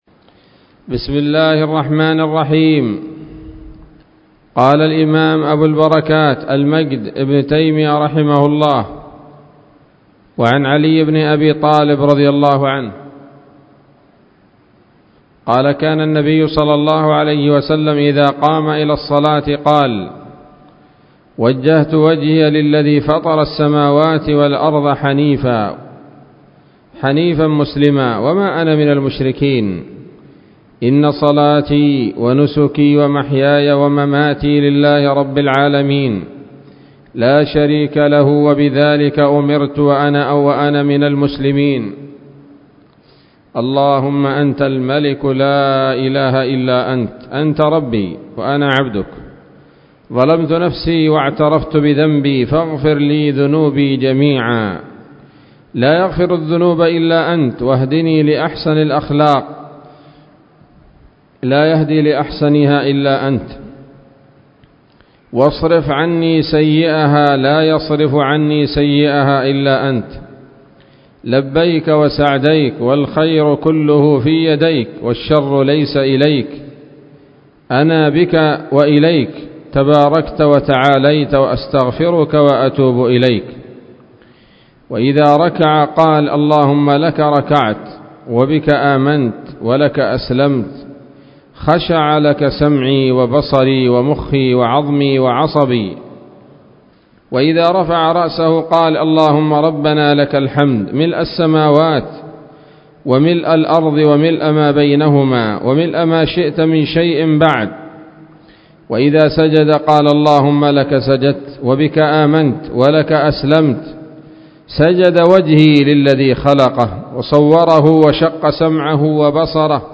الدرس السادس عشر من أبواب صفة الصلاة من نيل الأوطار